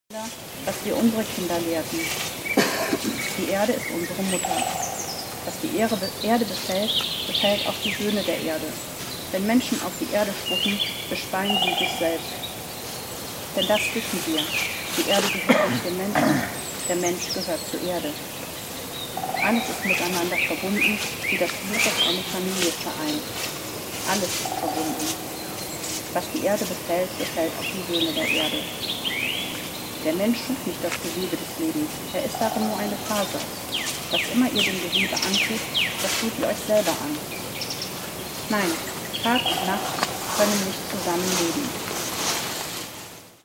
Vögelgezwitscher während der Rede (Ausschnitt) (Audio 2/2) [MP3]